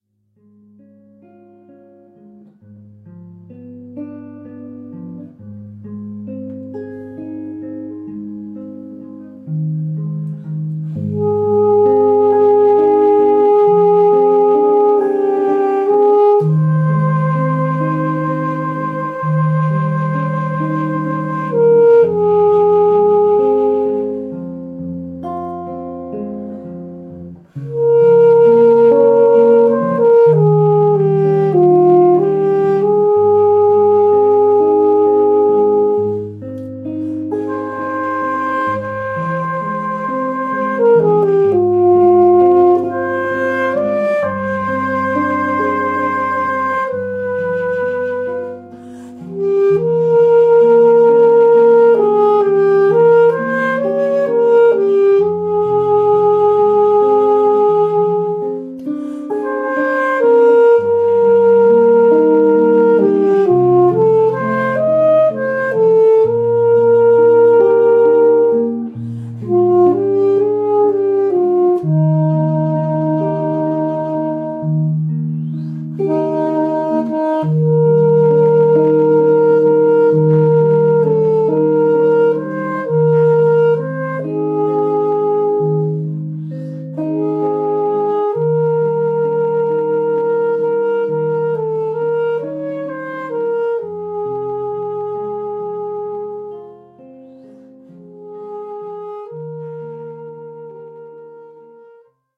Ave Maria (Alto Saxophone and Guitar Duo)